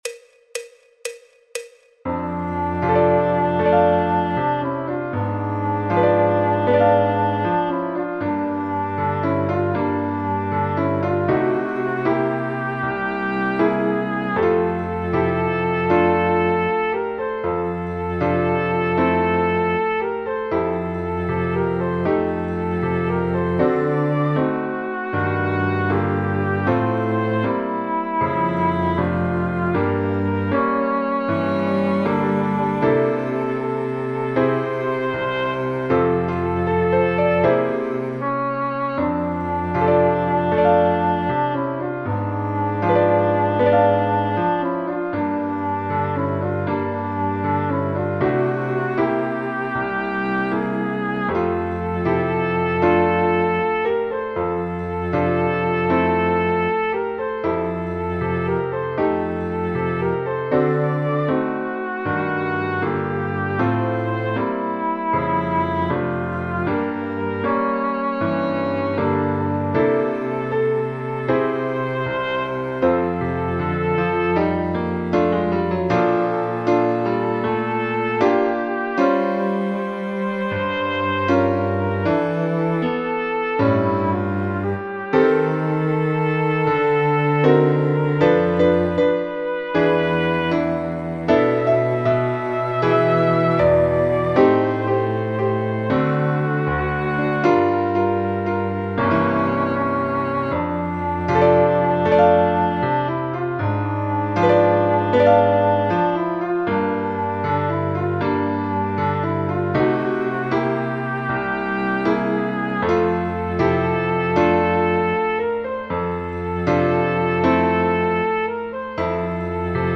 Do Mayor
Jazz, Popular/Tradicional